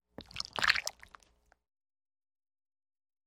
Звуки слайма
Шуршание движения слайма